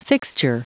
Prononciation du mot fixture en anglais (fichier audio)
Prononciation du mot : fixture